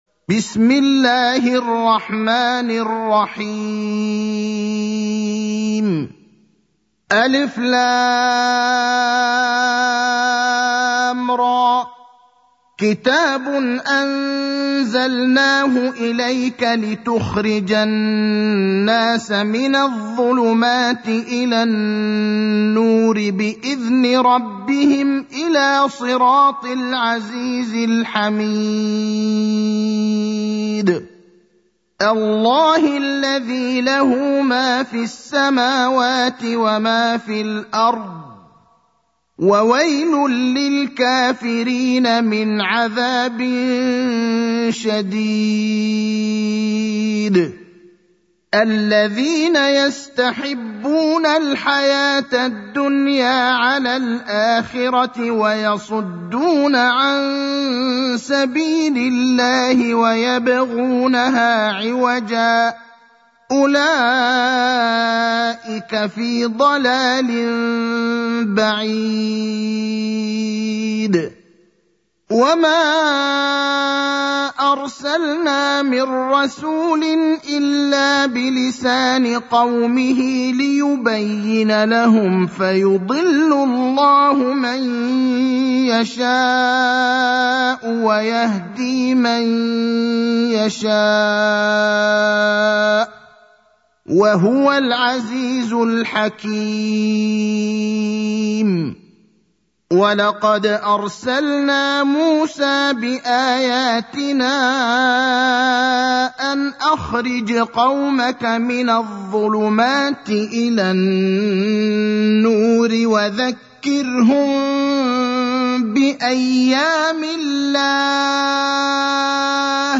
المكان: المسجد النبوي الشيخ: فضيلة الشيخ إبراهيم الأخضر فضيلة الشيخ إبراهيم الأخضر إبراهيم (14)